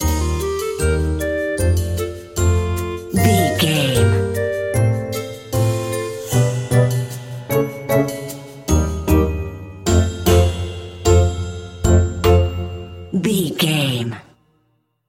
Ionian/Major
F#
Slow
orchestra
strings
flute
drums
circus
goofy
comical
cheerful
perky
Light hearted
quirky